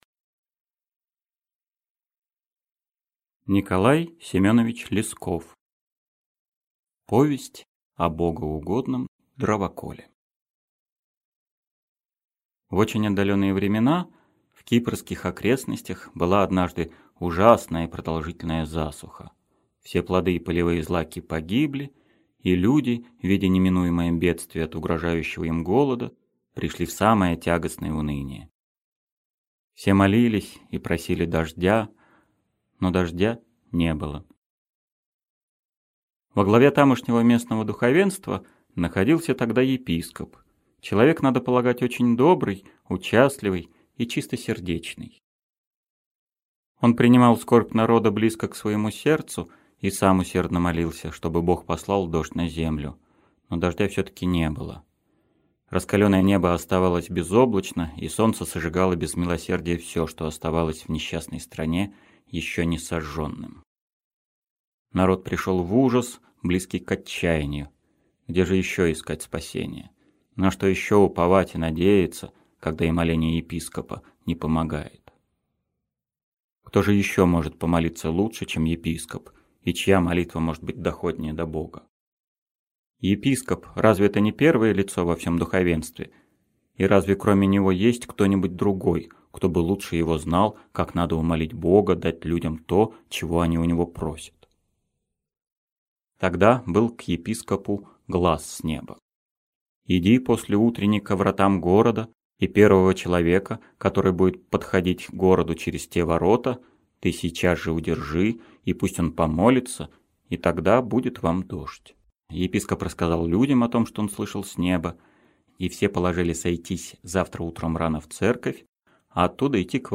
Аудиокнига Повесть о богоугодном дровоколе | Библиотека аудиокниг
Aудиокнига Повесть о богоугодном дровоколе Автор Николай Лесков Читает аудиокнигу Евгений Лебедев.